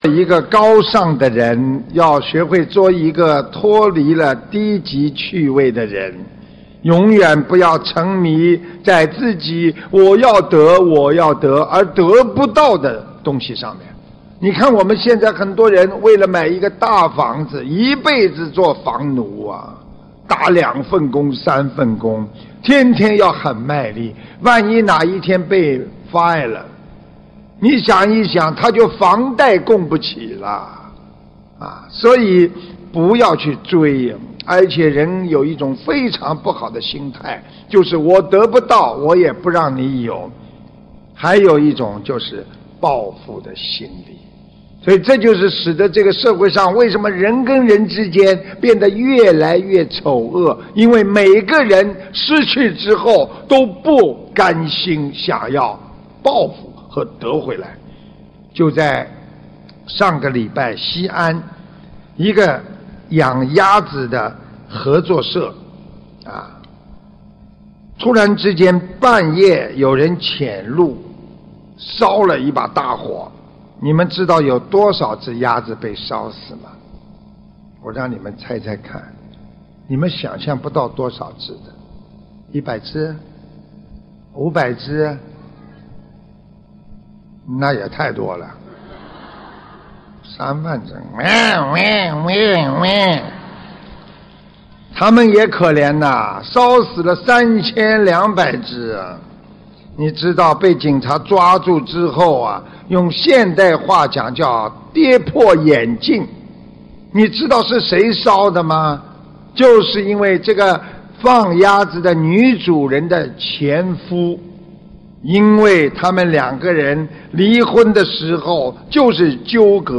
目录：法会开示_集锦